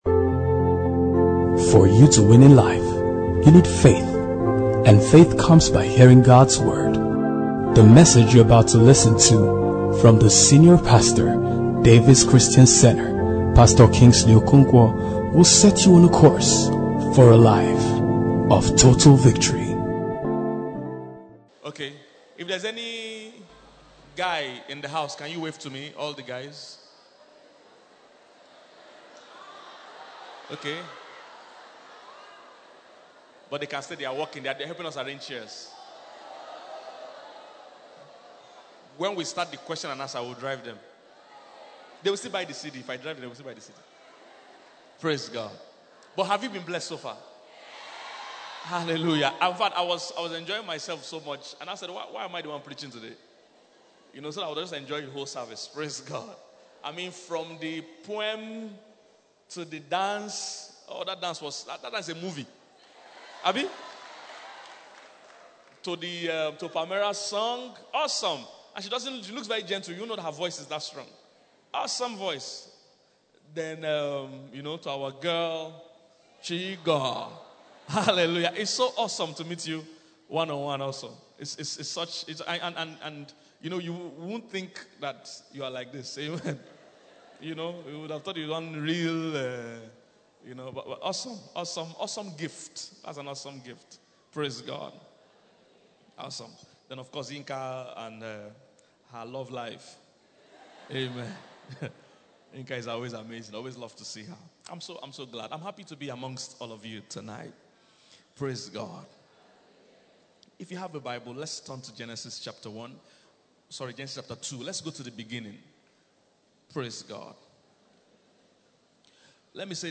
This is the most sincere relationship teaching I have ever heard?